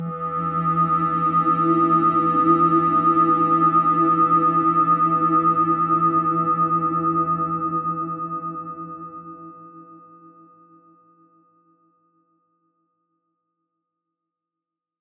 Мистическая атмосфера — звуковой кино эффект